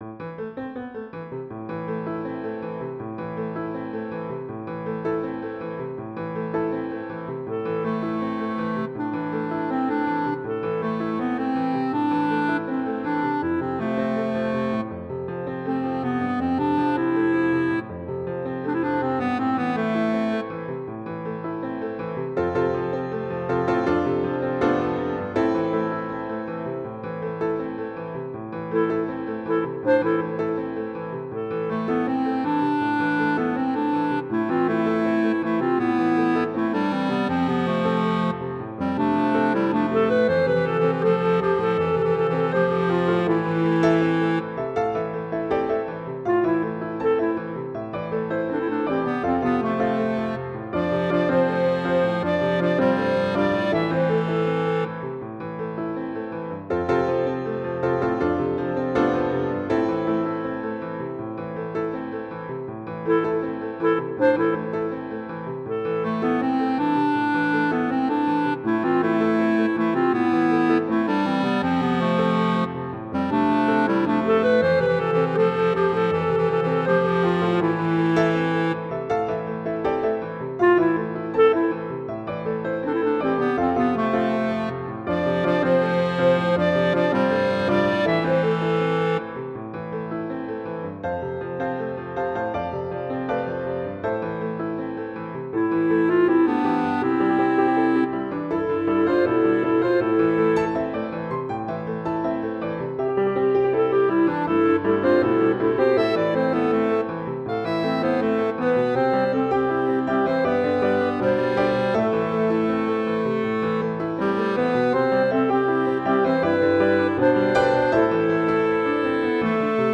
Title Glider Opus # 321 Year 2006 Duration 00:02:10 Self-Rating 3 Description Just a little flowy thing. mp3 download wav download Files
Trio, Piano, Clarinet Plays